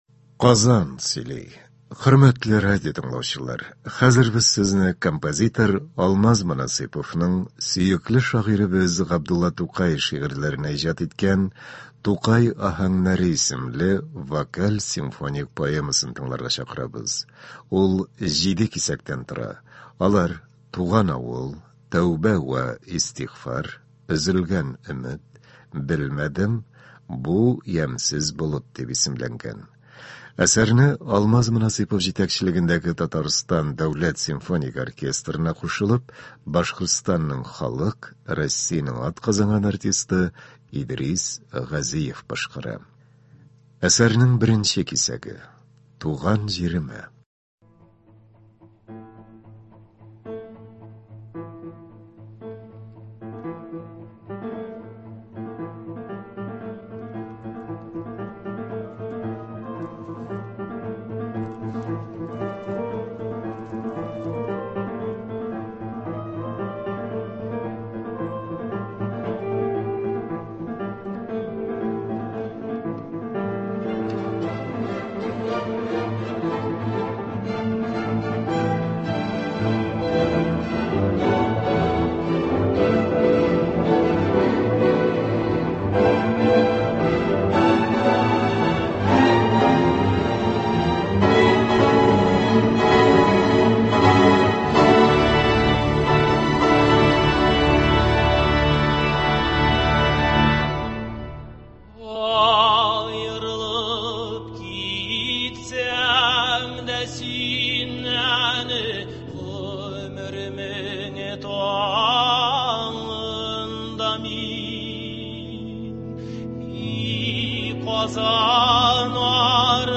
Көндезге концерт.